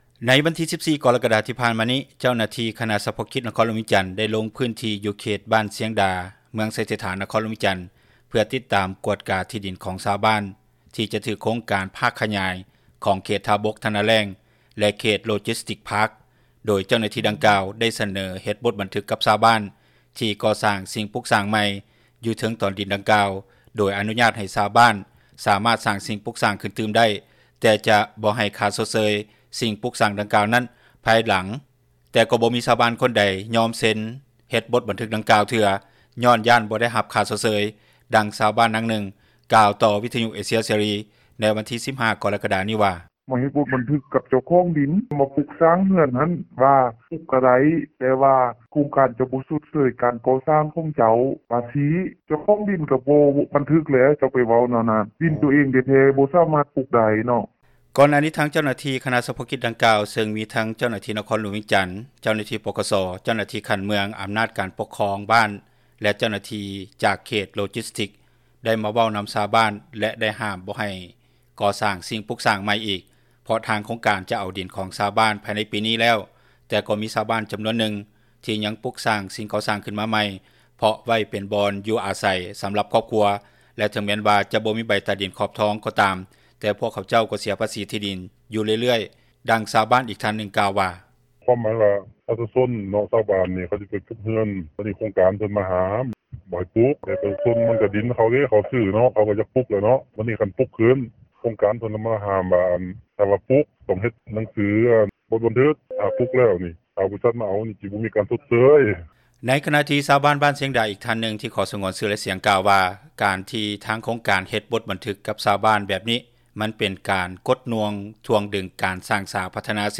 ດັ່ງຊາວບ້ານນາງນຶ່ງ ກ່າວຕໍ່ວິທຍຸເອເຊັຽເສຣີໃນວັນທີ 15 ກໍຣະກະດານີ້ວ່າ: